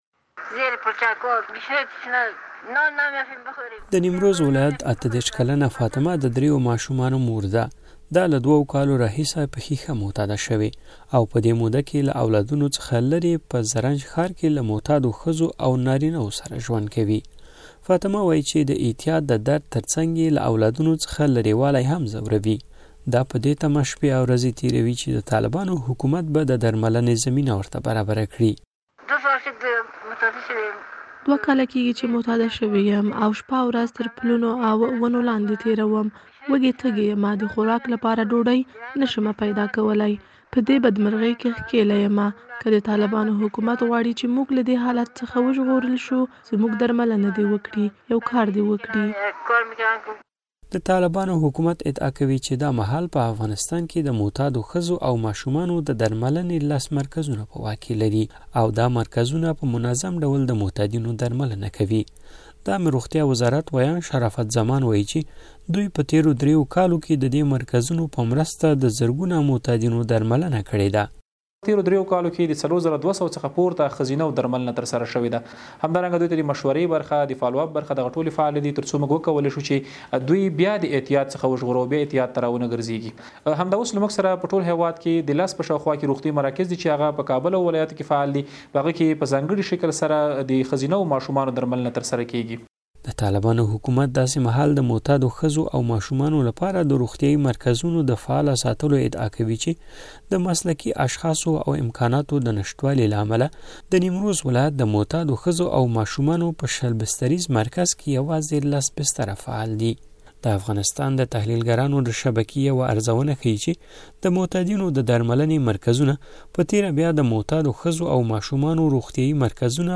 د معتادو مېرمنو په اړه راپور